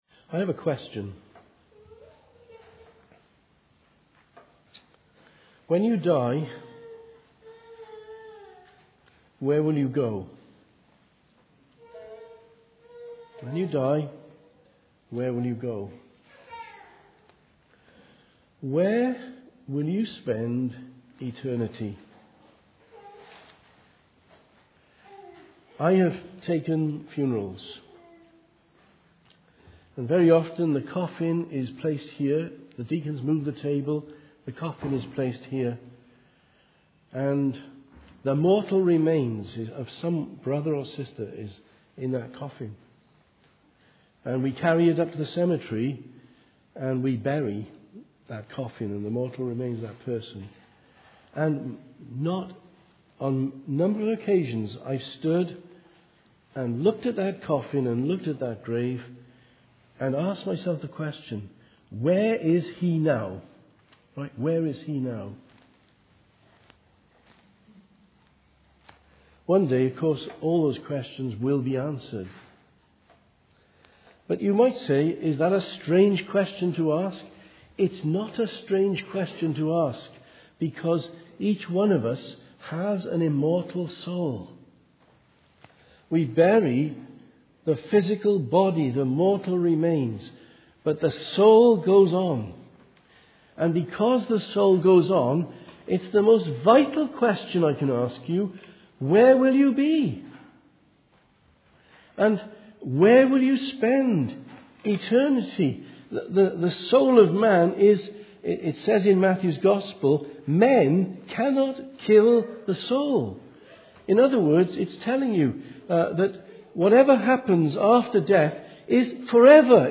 Sermons - Immanuel Presbyterian Church